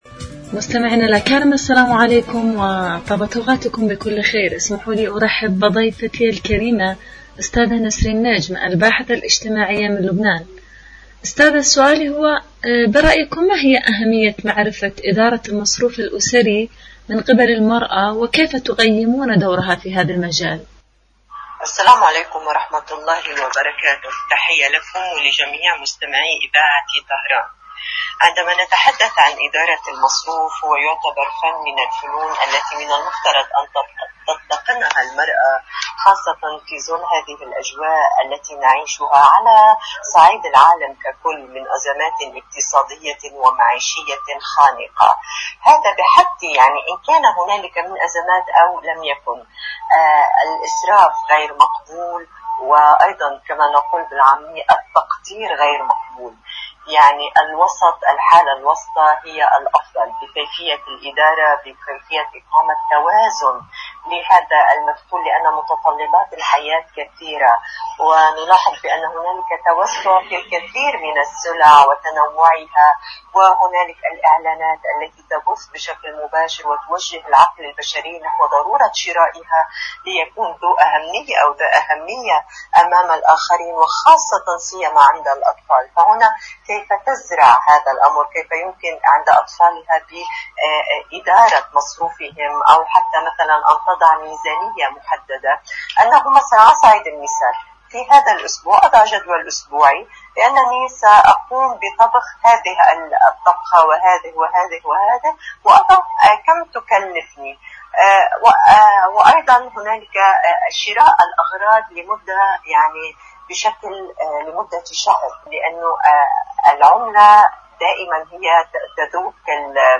إذاعة طهران-عالم المرأة: مقابلة إذاعية